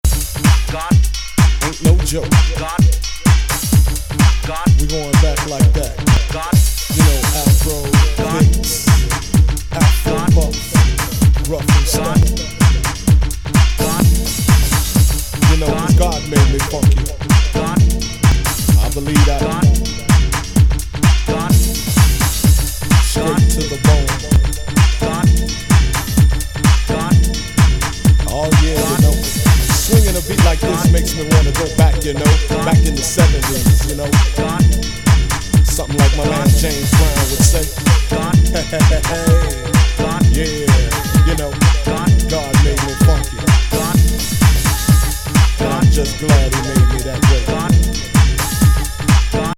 シカゴのスタジオでレコーディングされた、ゲットー・スタイルのUKハウス!